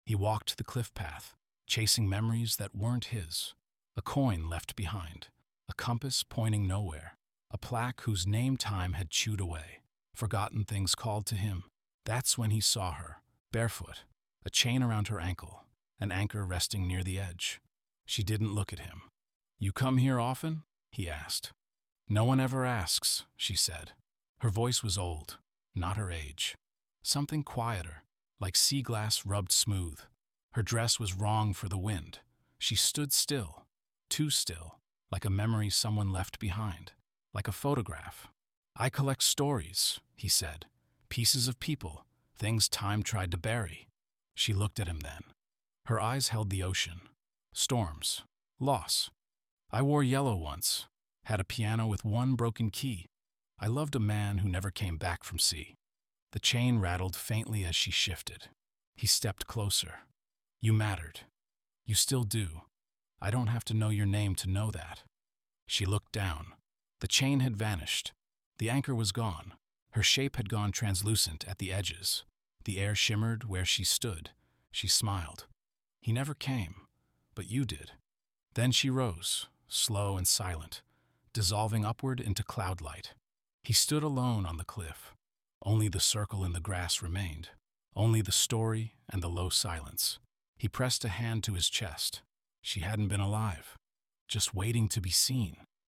Anchorlight | Audio Short Story